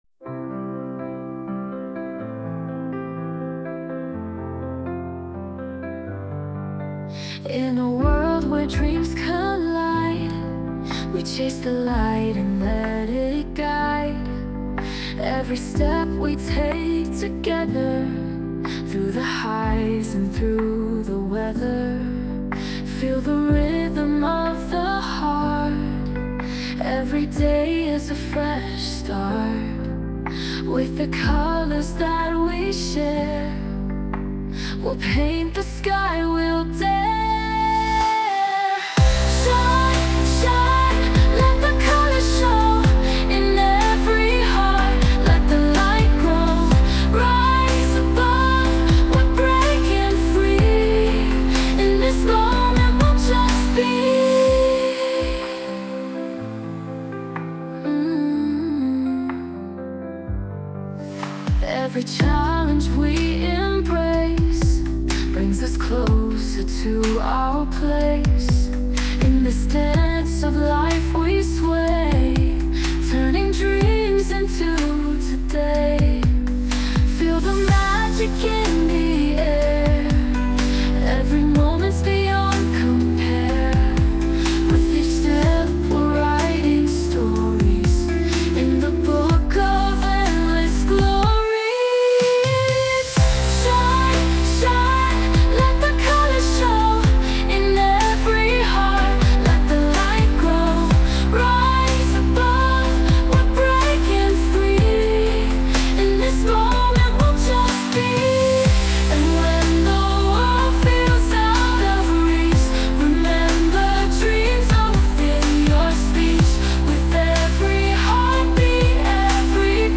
女性ボーカル洋楽 女性ボーカルエンドロール
著作権フリーオリジナルBGMです。
女性ボーカル（洋楽・英語）曲です。
すごく感動的で合うんじゃないかな。